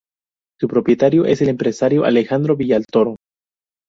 A‧le‧jan‧dro
/aleˈxandɾo/